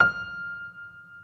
piano-sounds-dev
Vintage_Upright